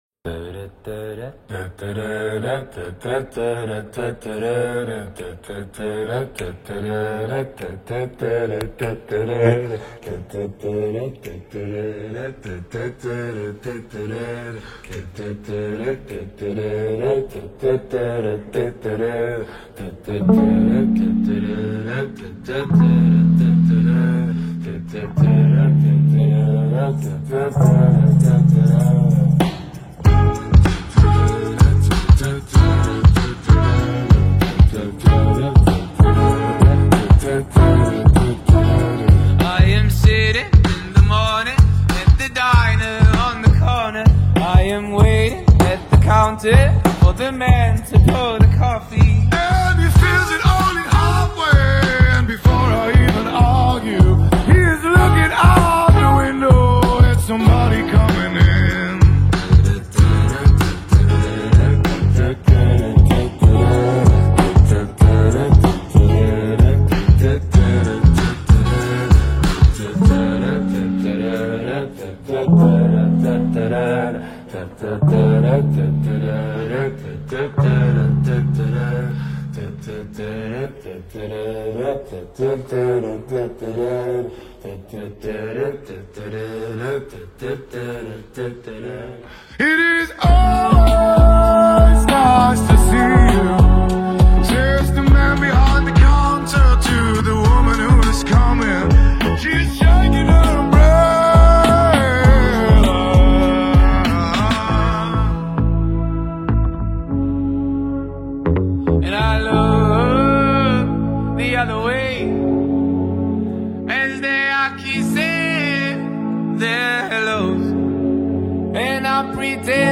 نسخه 8 بعدی